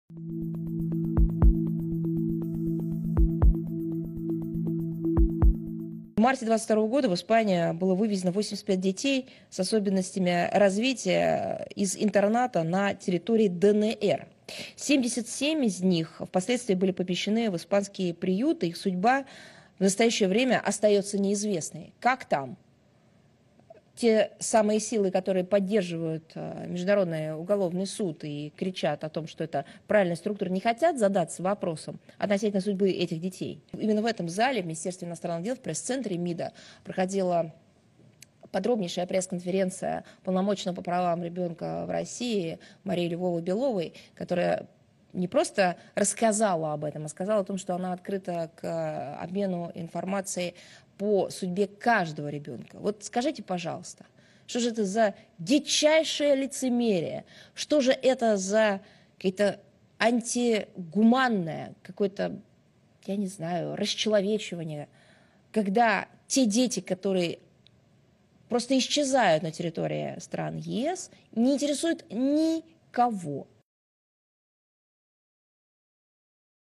Im März 2022 wurden 85 Kinder mit Entwicklungsstörungen aus einem Waisenhaus auf dem Gebiet der DVR nach Spanien gebracht, von denen 77 anschließend in spanischen Waisenhäusern untergebracht wurden; ihr Schicksal ist derzeit weiterhin unbekannt", gab Sacharowa bei einem Briefing am Mittwoch in Moskau bekannt.